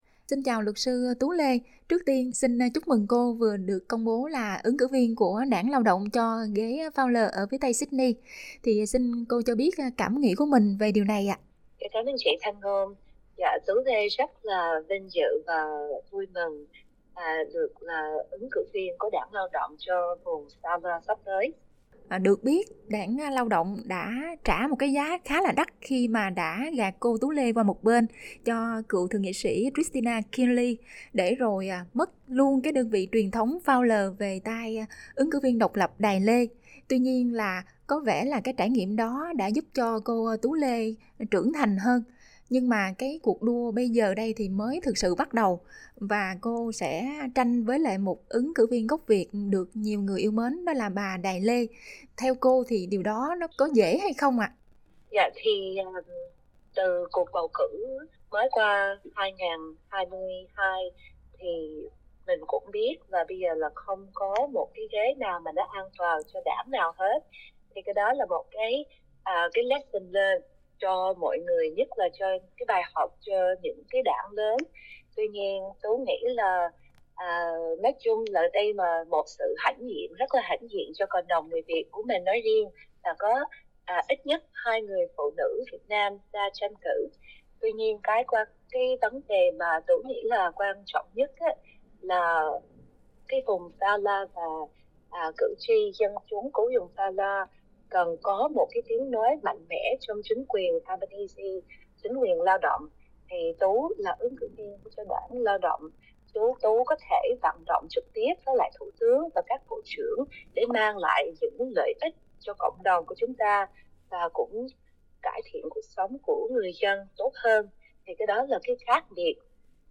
Trả lời phỏng vấn của SBS Tiếng Việt